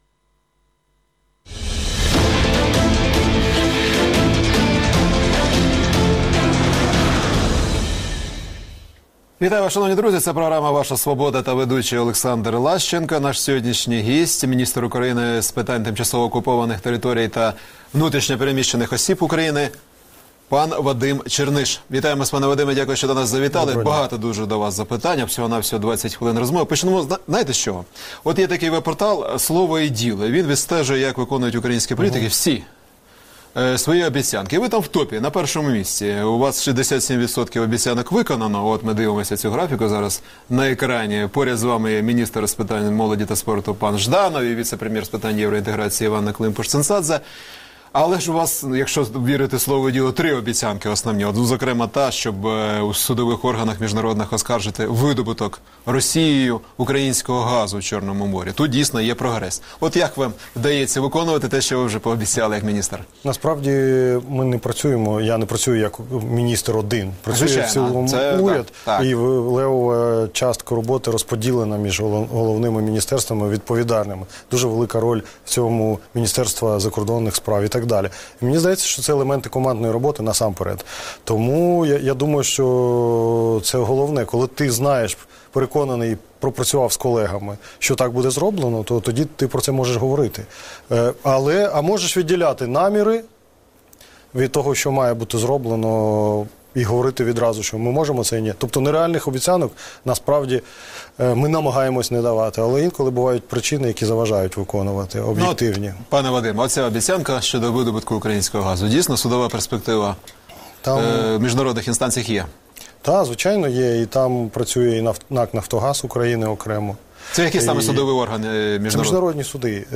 Ваша Свобода | Інтерв'ю з міністром з питань тимчасово окупованих територій та внутрішньо переміщених осіб України Вадимом Чернишем